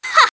One of Daisy's voice clips in Mario Kart 7